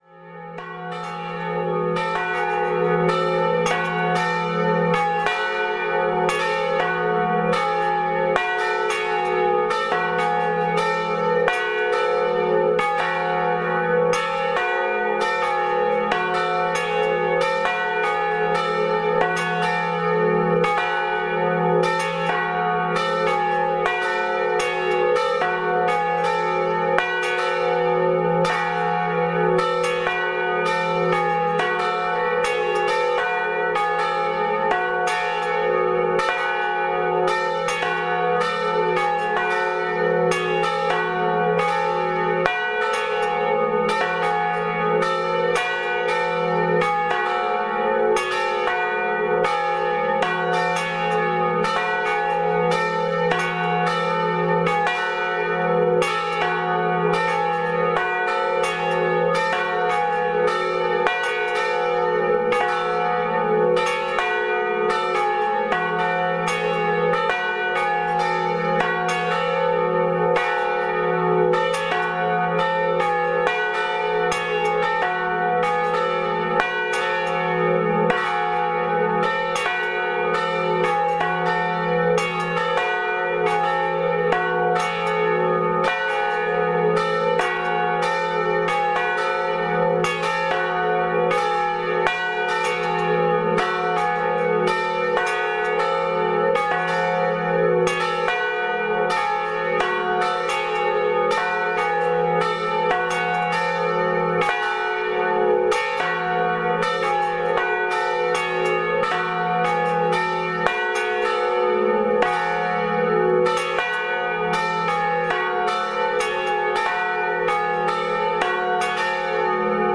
Lors de la volée, le battant colle à la pince, ce qui produit un son de cliquettement, de frottement, et ça atténue la durée de vie du son.
(3) La grande volée pour un mariage.